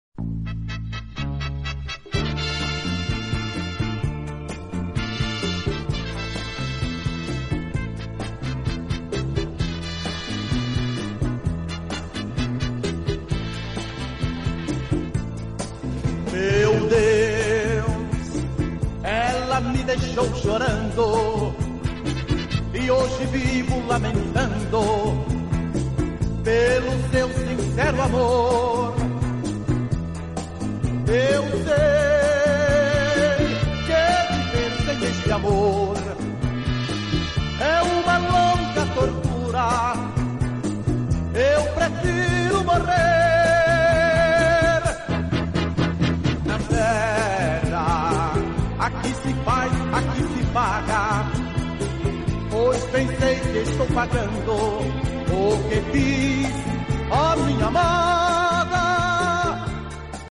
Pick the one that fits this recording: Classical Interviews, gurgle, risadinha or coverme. risadinha